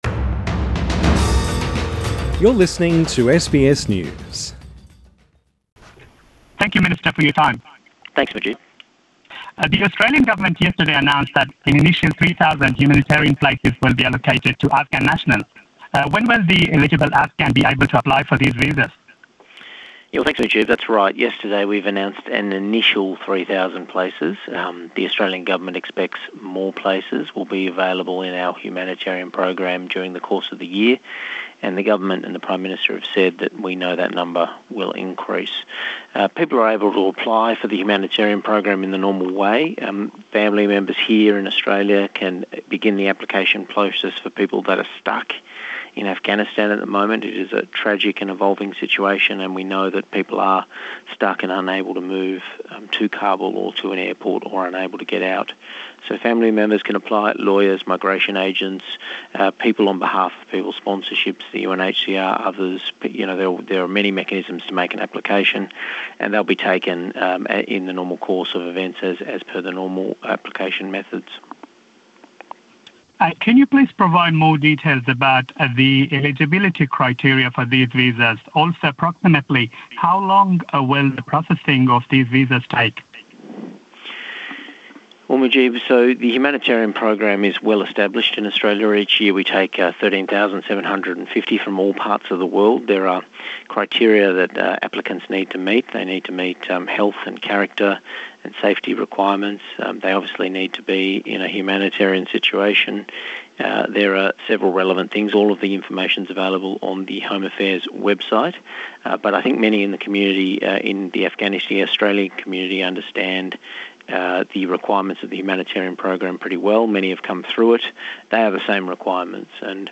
Interview with Alex Hawke, Immigration Minister